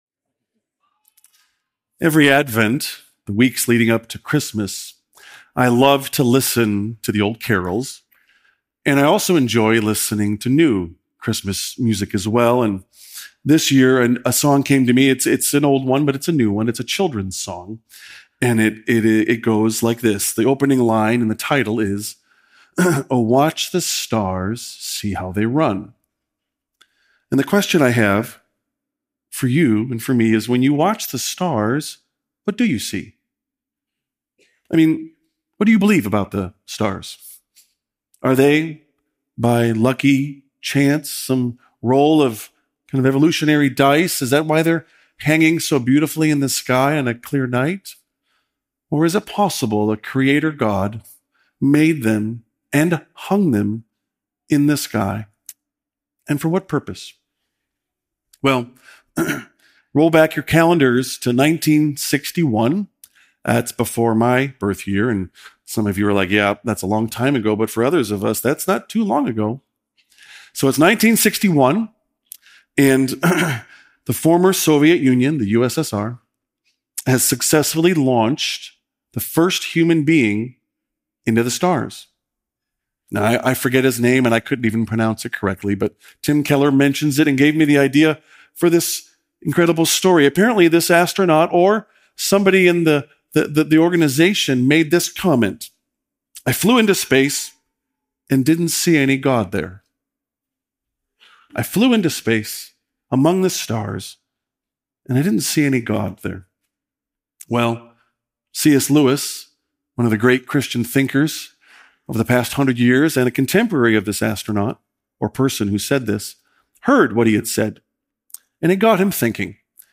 Watch Listen Christmas Eve sermon Scripture References